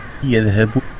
speech
keyword-spotting
speech-commands